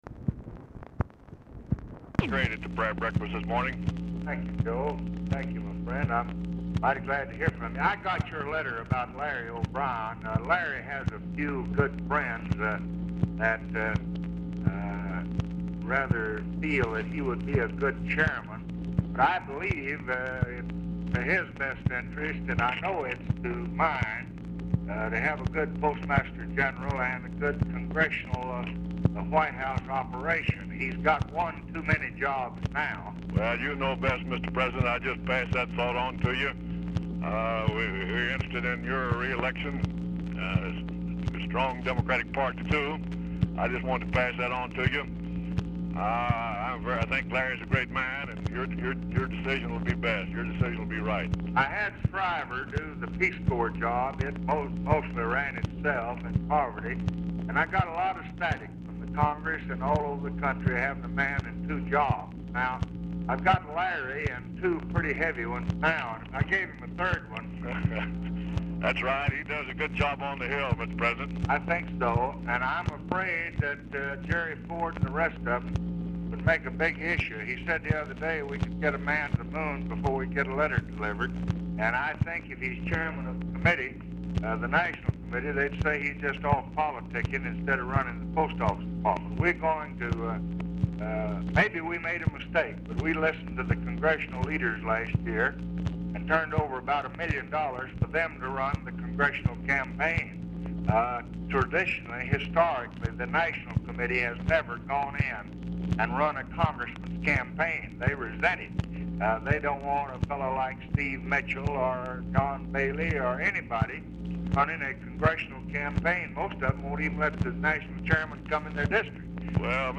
RECORDING STARTS AFTER CONVERSATION HAS BEGUN
Format Dictation belt
Location Of Speaker 1 Oval Office or unknown location
Specific Item Type Telephone conversation Subject Appointments And Nominations Congressional Relations Elections National Politics Public Relations